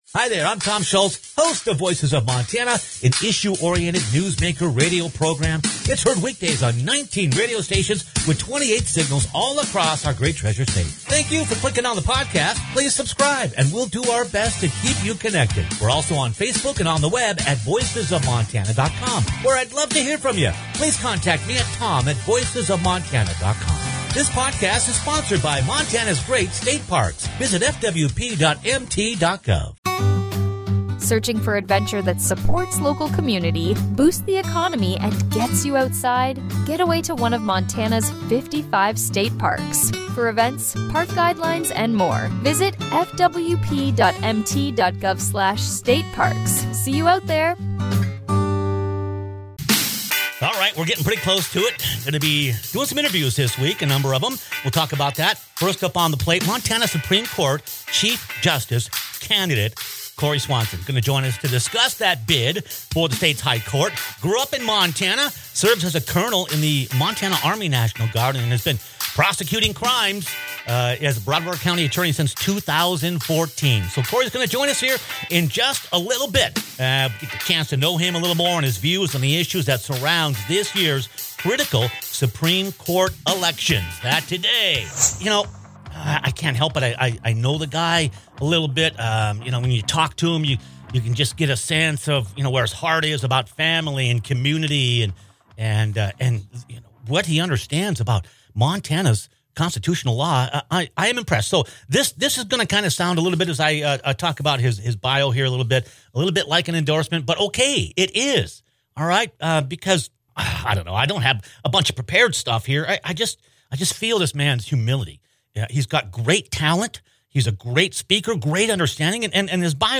Montana Supreme Court Chief Justice Candidate Cory Swanson joins us to discuss his bid for the state’s high court. Swanson grew up in Montana, serves as a Col. in the Montana Army National Guard and has been prosecuting crime as the Broadwater County Attorney since 2014.